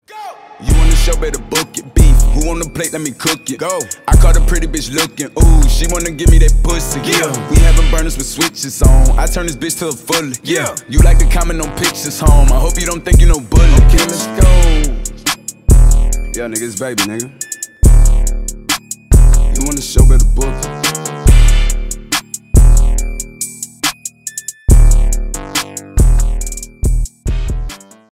бесплатный рингтон в виде самого яркого фрагмента из песни
Рэп и Хип Хоп
громкие